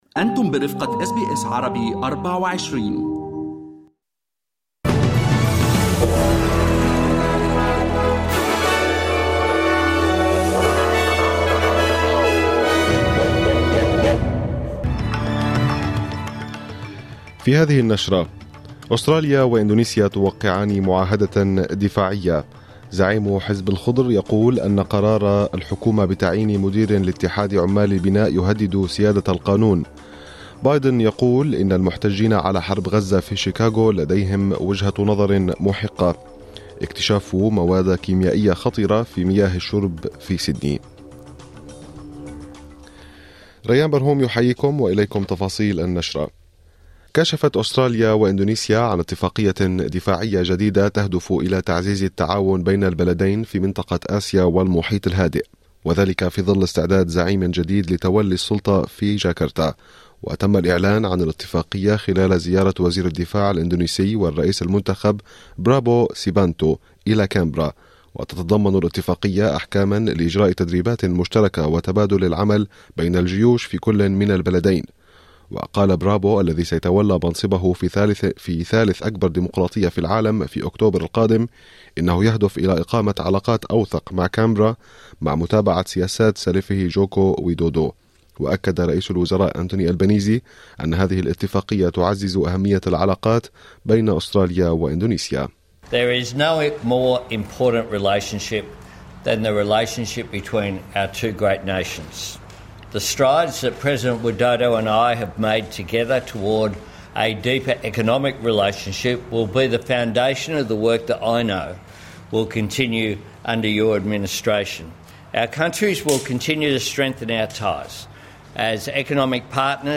نشرة أخبار المساء 20/8/2024